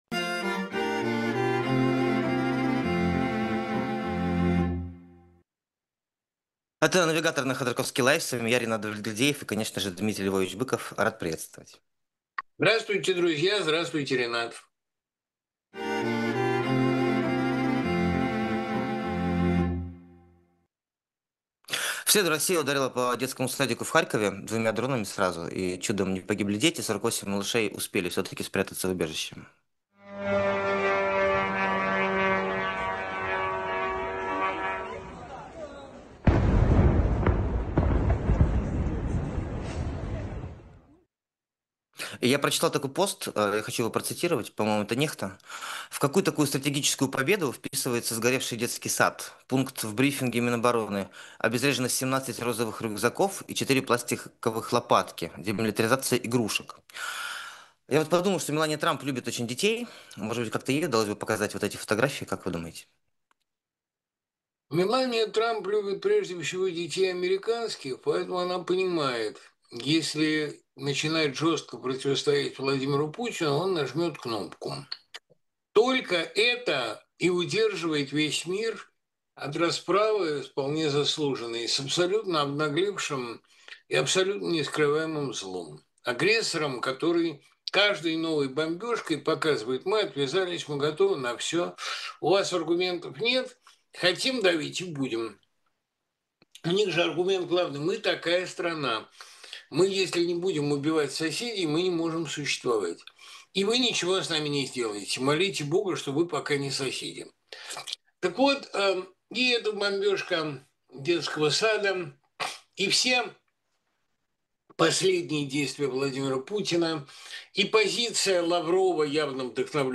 Дмитрий Быков поэт, писатель, журналист